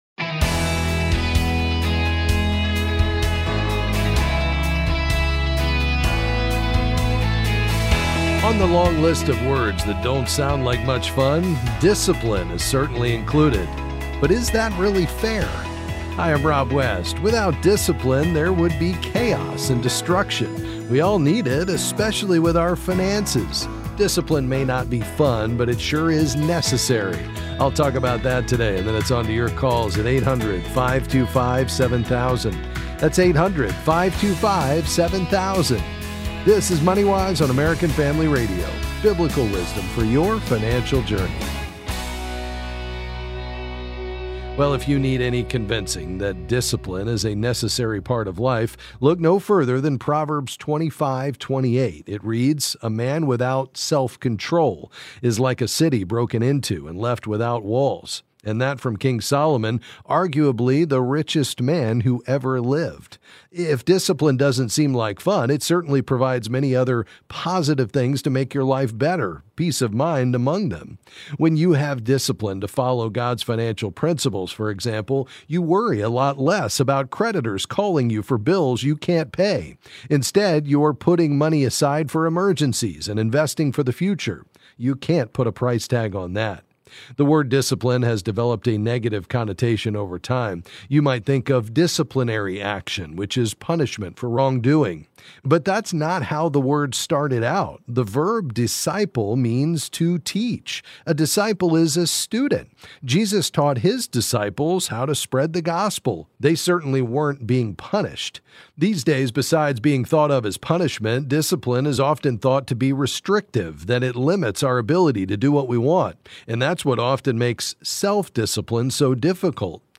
Then he’ll answer your questions on various financial topics.